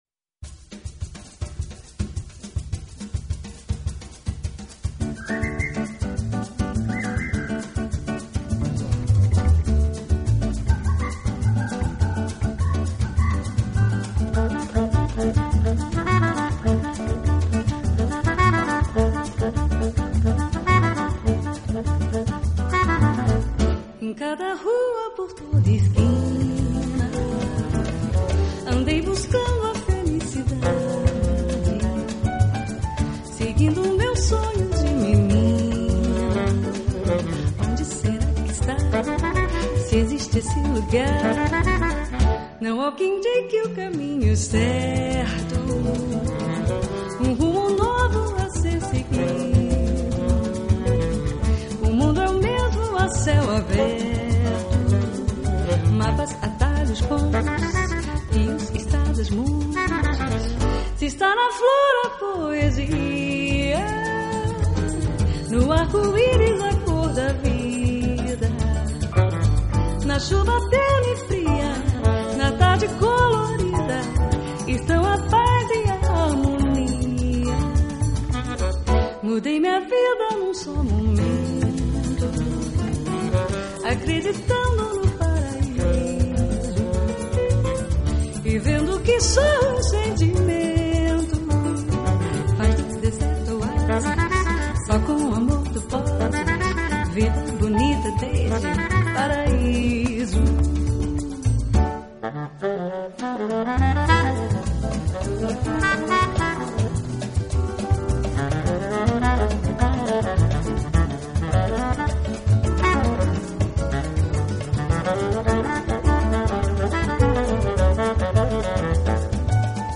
音樂類別 ： 巴西爵士Bossa Nova
低音薩克斯風∕鋼琴
演出，整張專輯充滿著自在活潑的節奏，輕鬆、宜人，真是一張陽光沙灘之作。